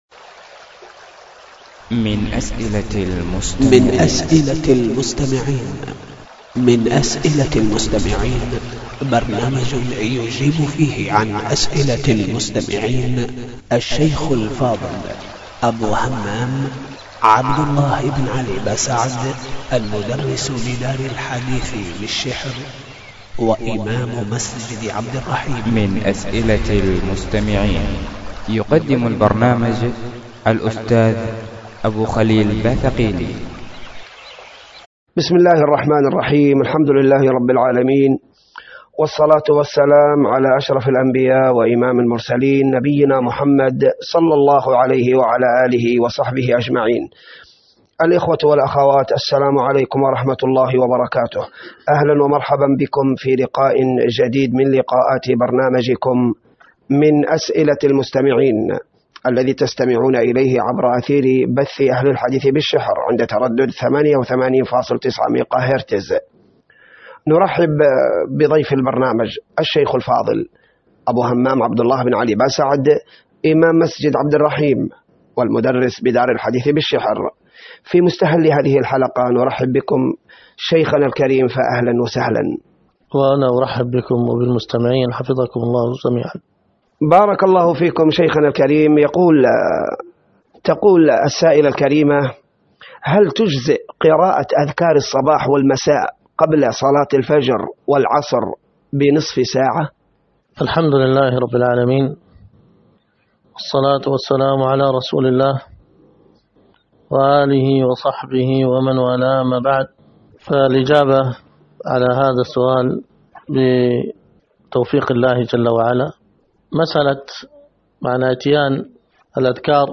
الدرس في الصحيح المسند من دلائل النبوة 2، الدرس الثاني:من( وذكرت أيضا فصلا في دلائل النبوة التي أخبر ... من رآهم بعباد المشركين من الهند الذين يعبدون الأنداد ).